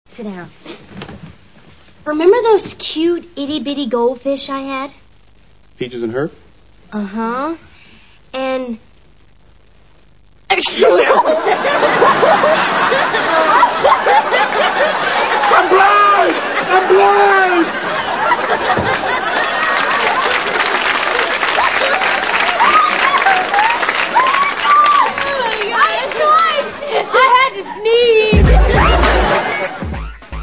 Bloopers.
This is the one where Tamera accidentally sneezes on Ray in the middle of a line...   118 Kb